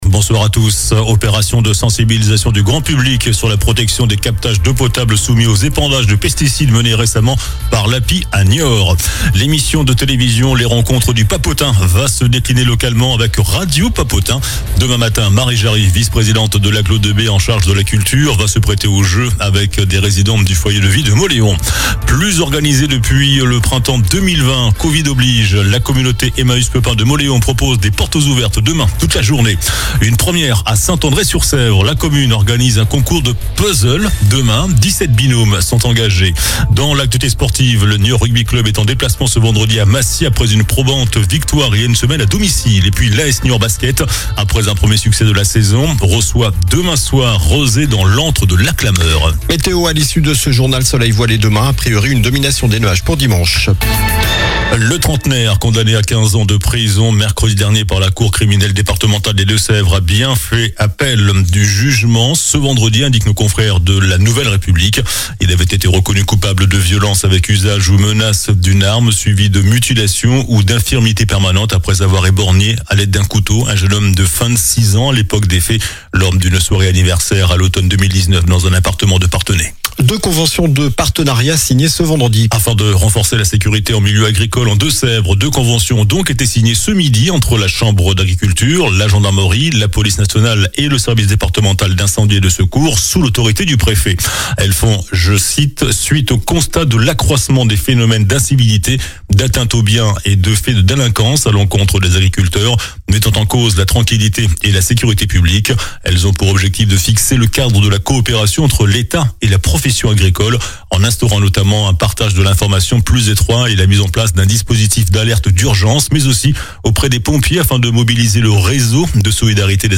JOURNAL DU VENDREDI 17 OCTOBRE ( SOIR )